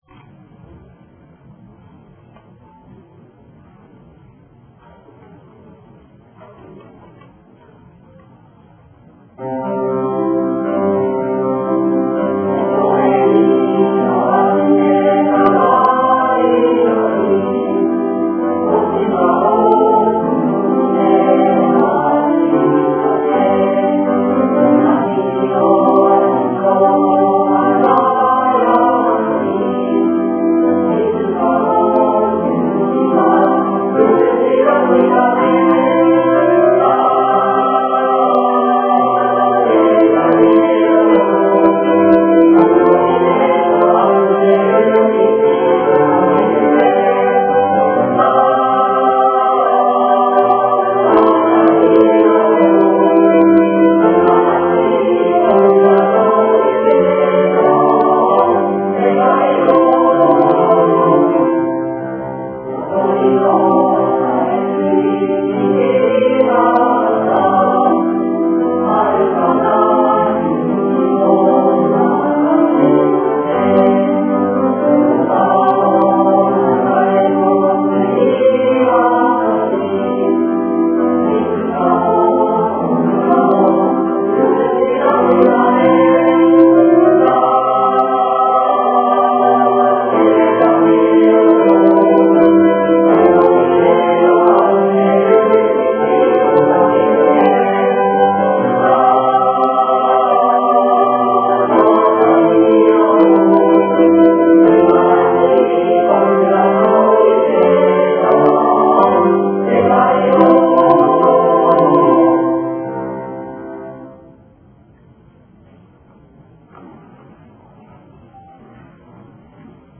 合唱コンクール(音声あり)
20日(木)に行われました合唱コンクールの音声になります。